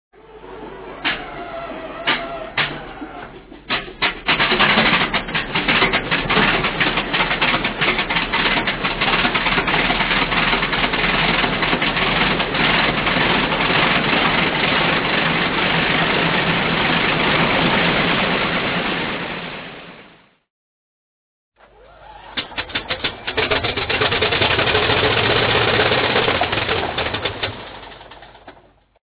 Here are a couple of radial starts the one you can clearly hear the inertial starter...so sweet, so sweet Attached Files Inertial Starter.mp3 (21.7 KB, 289 views) start.mp3 (57.5 KB, 238 views)